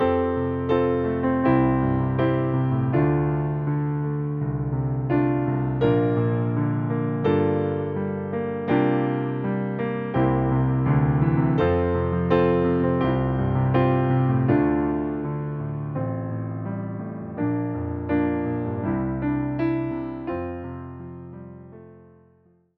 Nastrojowy podkład fortepianowy do hymnu
Wersja demonstracyjna:
83 BPM
F – dur